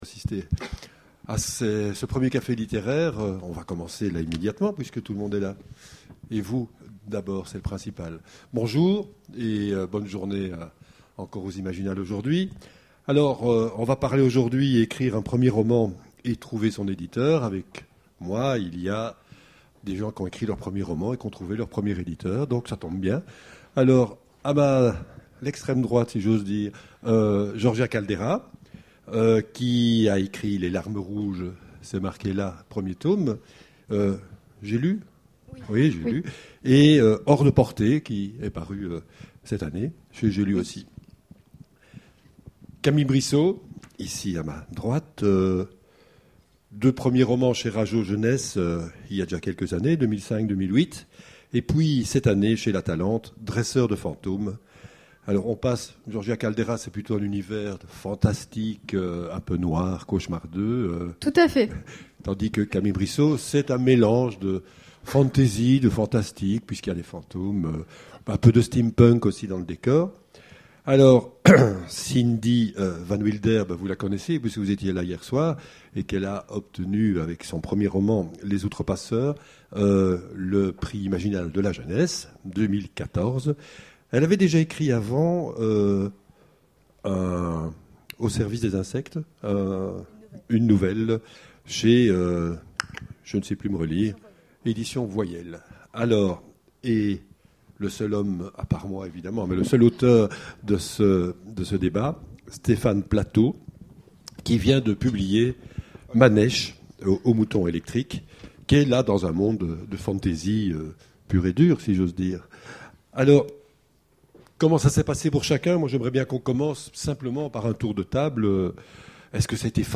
Imaginaires 2014 : Conférence Ecrire un premier roman et trouver son éditeur